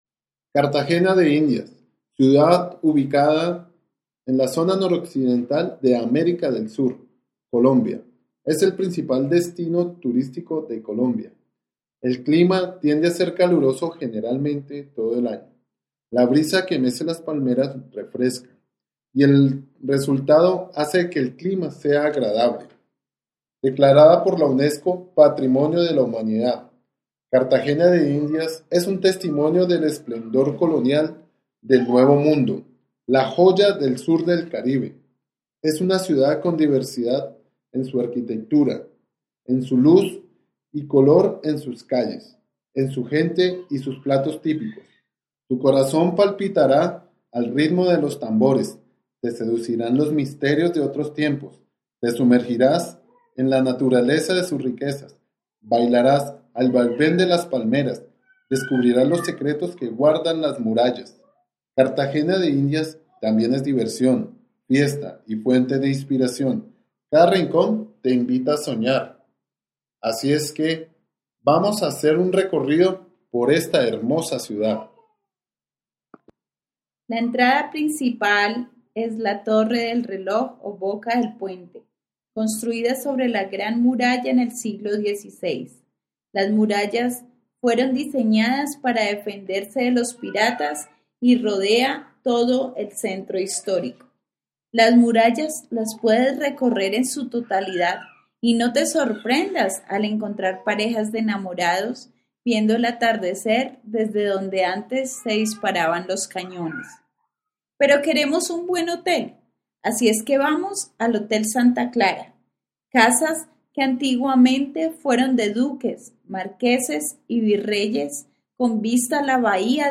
As you listen to the guide taking you around Cartagena, put the photos in order of the places you visit.